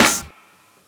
CLP_SNR.wav